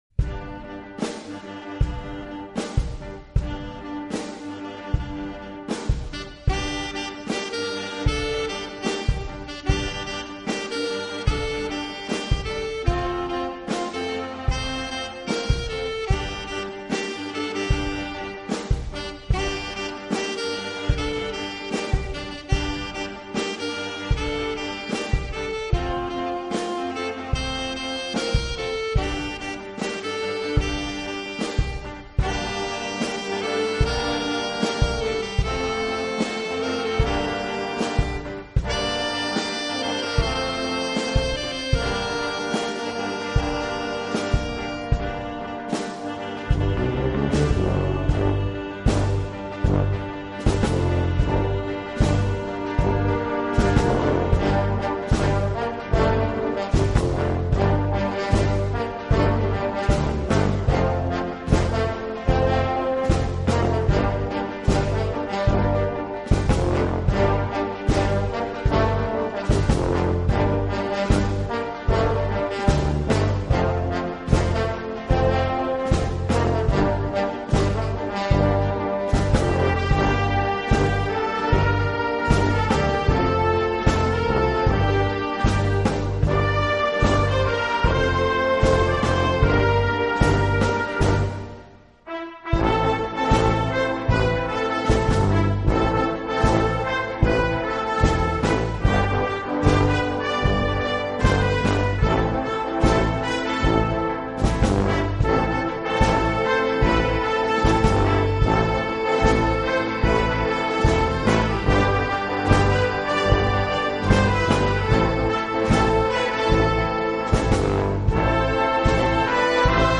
Gattung: Moderne Blasmusik
A4 Besetzung: Blasorchester Zu hören auf